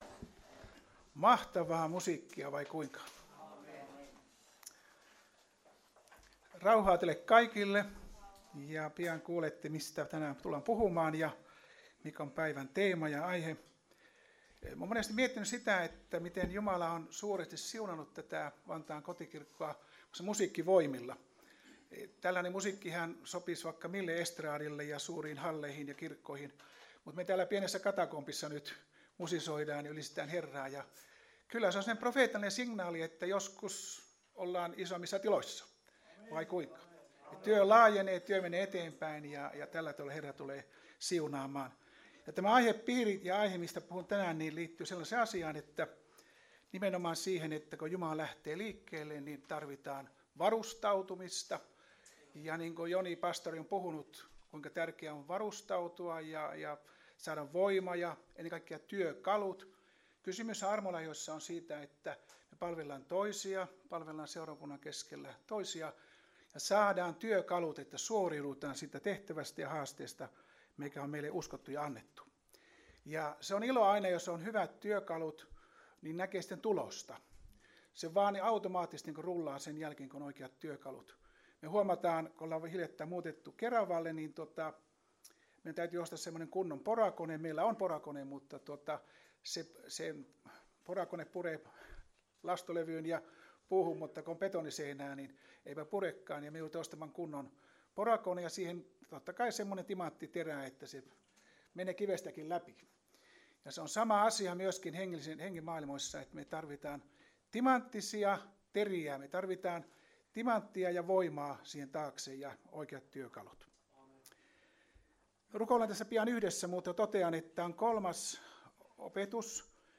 Service Type: Raamattutunti